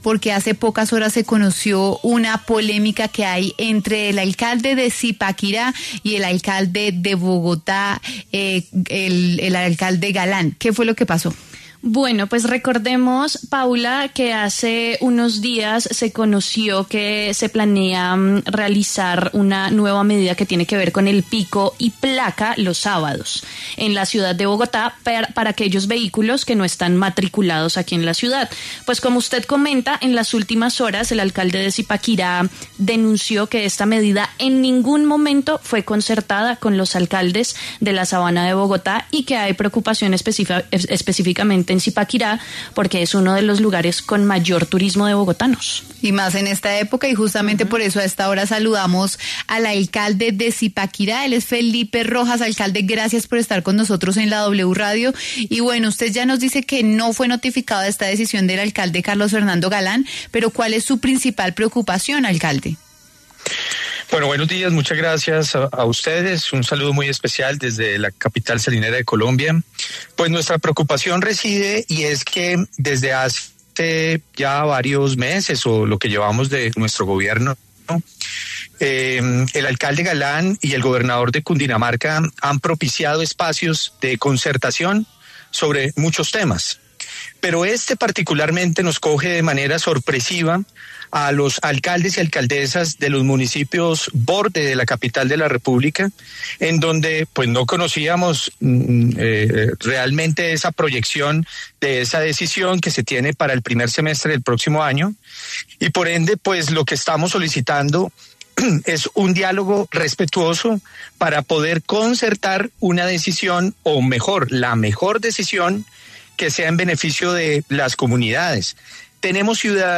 El alcalde de Zipaquirá, Fabián Rojas, conversó con W Fin De Semana a propósito de las medidas de movilidad que anunció el mandatario de Bogotá, Carlos Fernando Galán, para vehículos que estén matriculados en otros municipios del país.